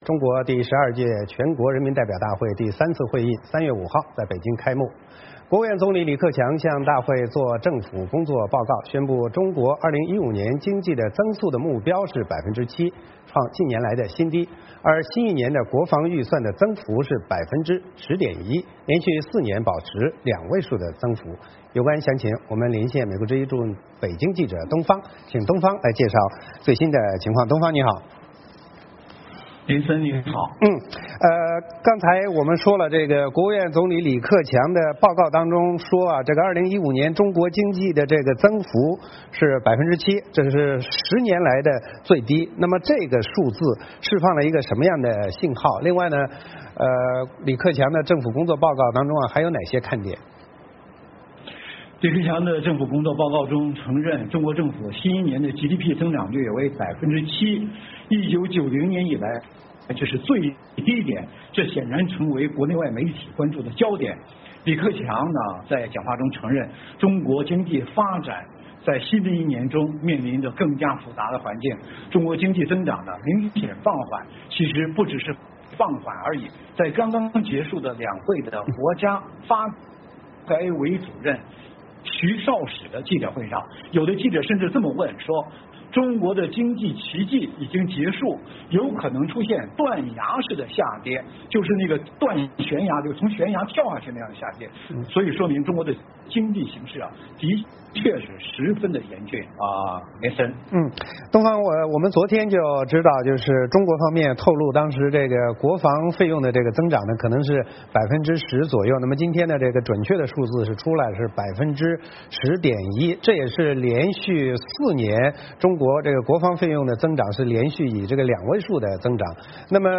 VOA连线：2015中国经济增速7%， 创十年最低